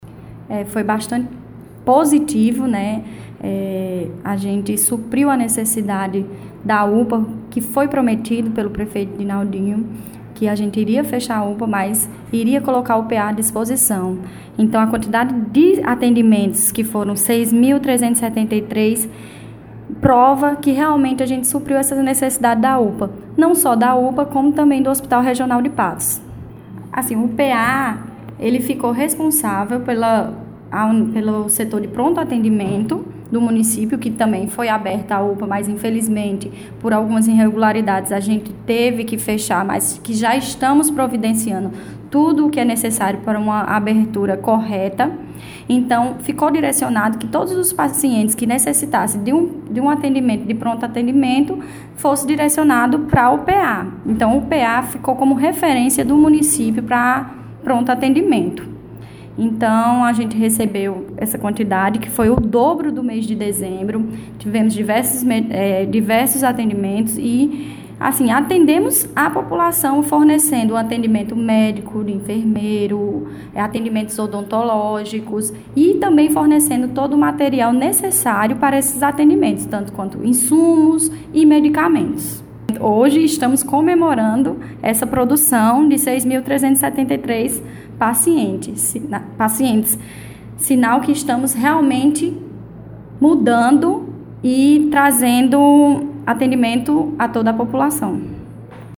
Fala da secretária de Saúde, Andressa Lopes